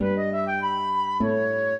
flute-harp
minuet14-10.wav